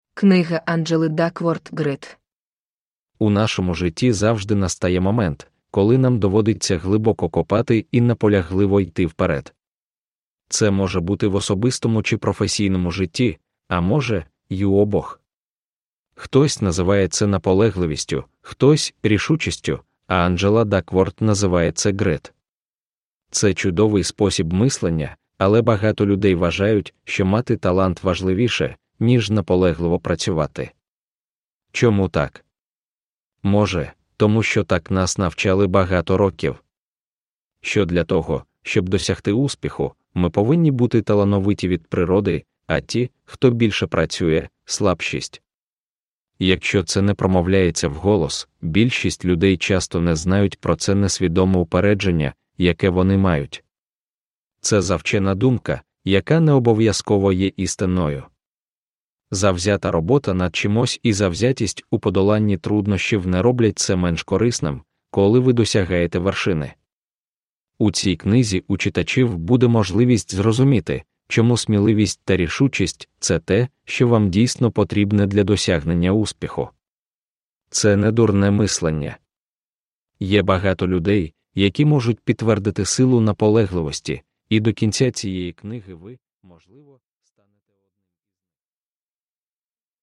Grit – Ljudbok – Laddas ner
Uppläsare: Reedz Audiobooks